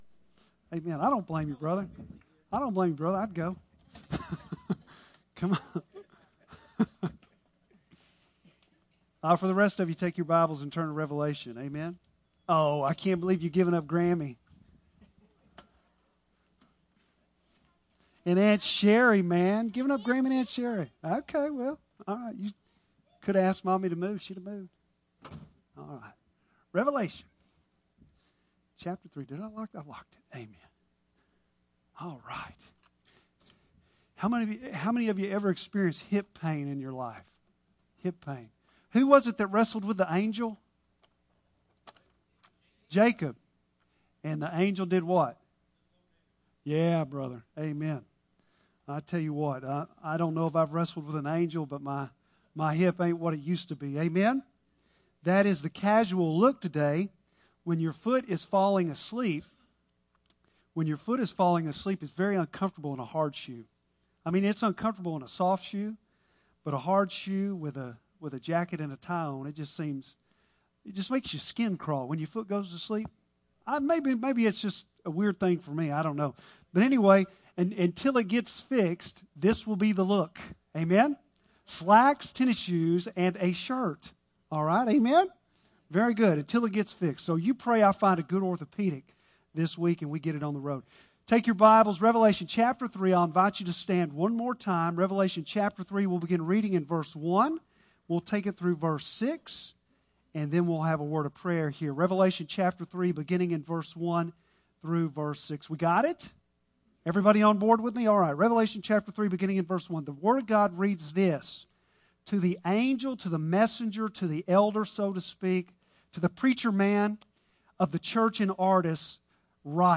Bible Text: Revelation 3:1-6 | Preacher